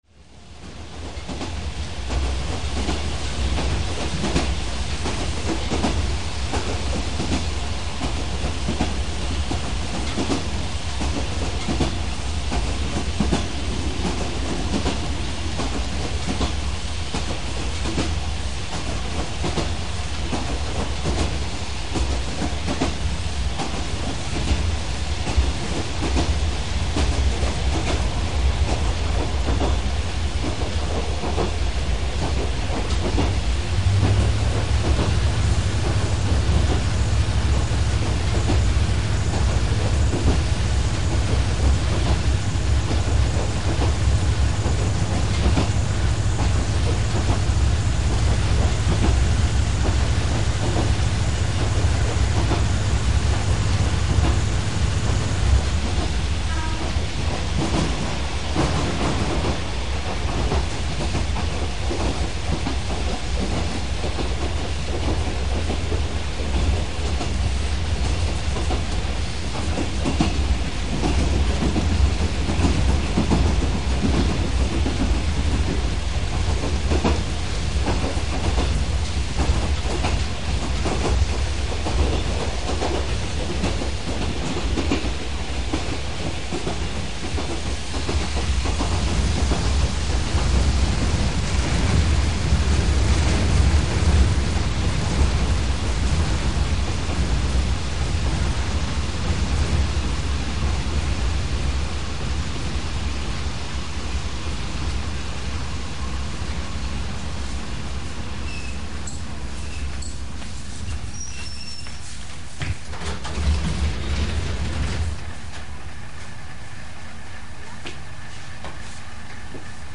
同じ列車。途中から回りだしたエンジン　和歌山線キハ３５　　隅田到着～下兵庫
隅田駅手前の力行からちゃんと回りだしたキハ３５のエンジン。隅田からは何事もなかったみたいに調子よく走ります。